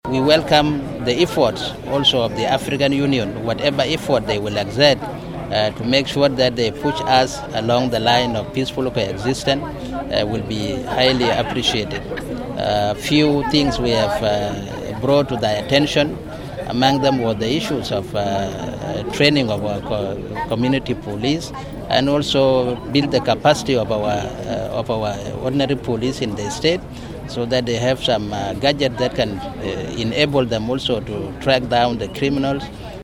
Addressing the team in Bor town this afternoon, Antipas Nyok says this is a temporary measure until the necessary unified forces are graduated and deployed.